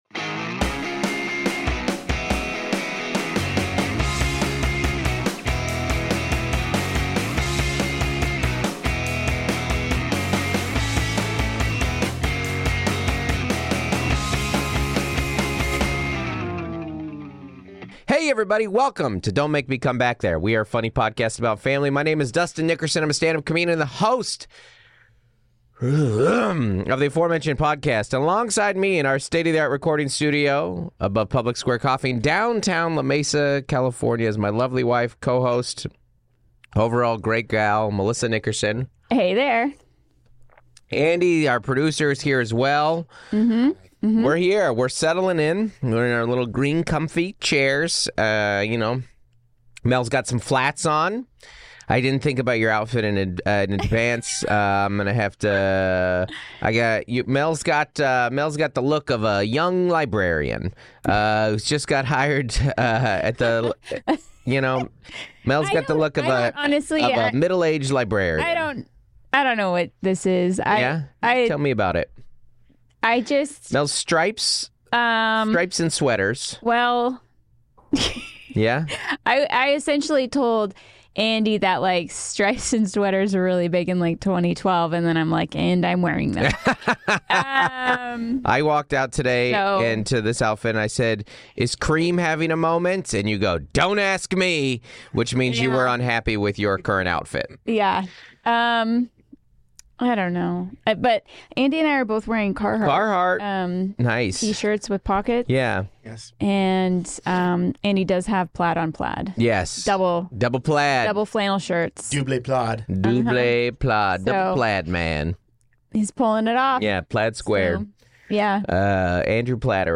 recorded in studio, La Mesa ,CA.